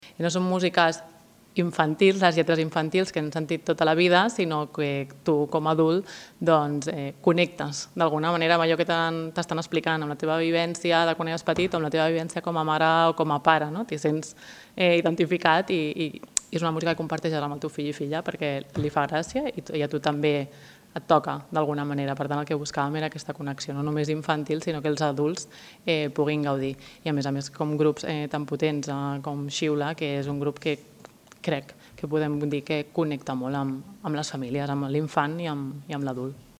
Presentació El Petit
Belén Leiva, regidora d'Infància i Adolescència